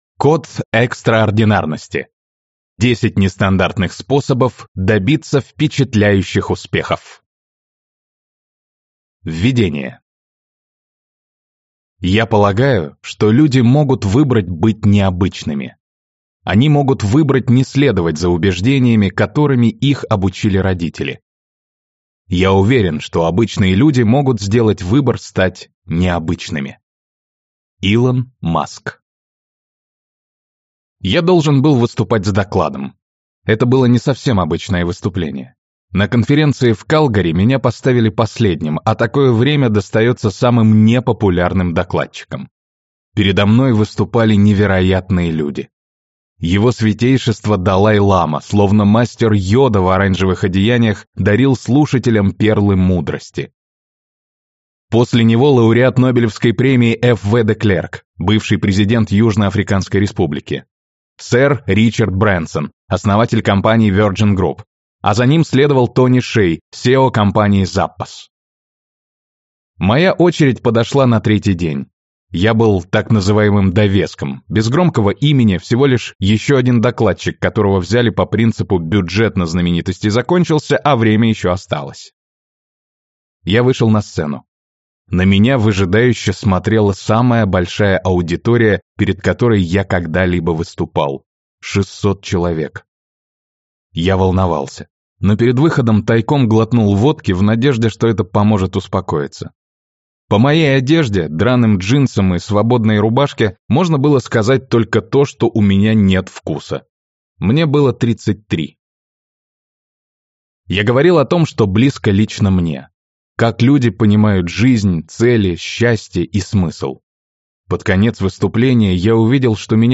Аудиокнига Код экстраординарности. 10 нестандартных способов добиться впечатляющих успехов | Библиотека аудиокниг